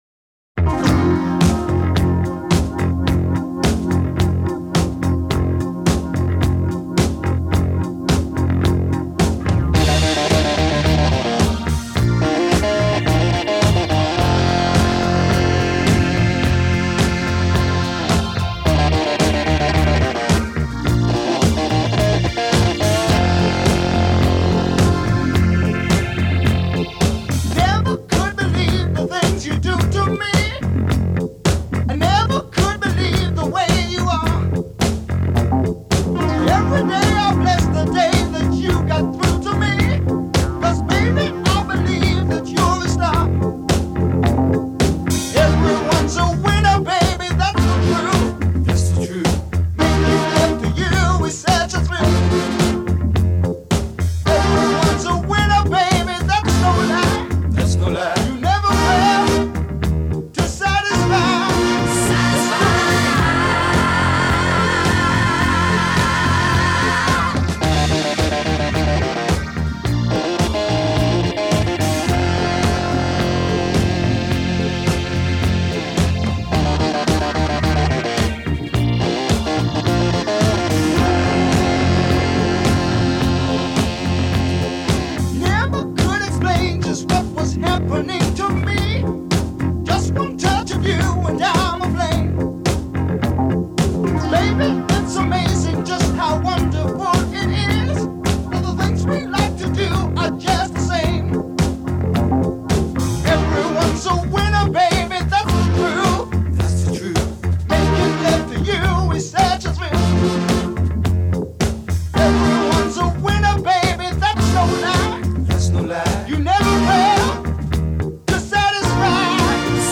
стили фанк и соул с элементами поп-рока и регги